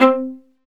Index of /90_sSampleCDs/Roland L-CD702/VOL-1/STR_Viola Solo/STR_Vla1 % marc